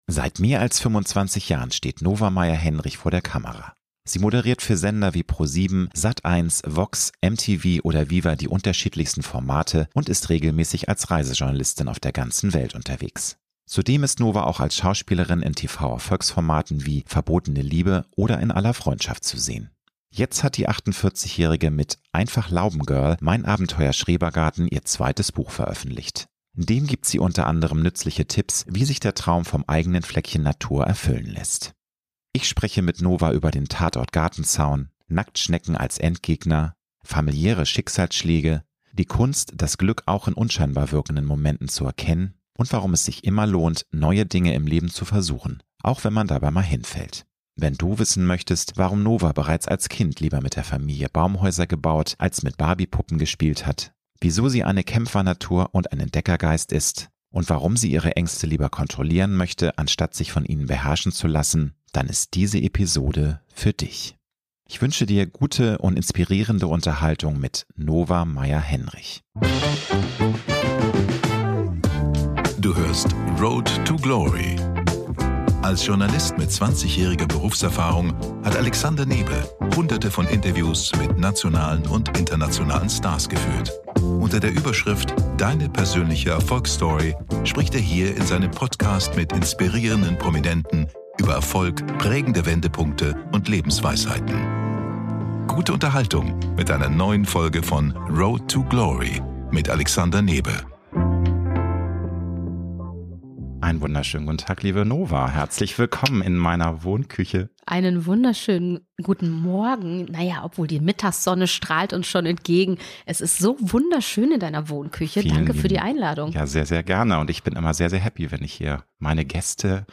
Ich wünsche Dir gute und inspirierende Unterhaltung mit Nova Meierhenrich.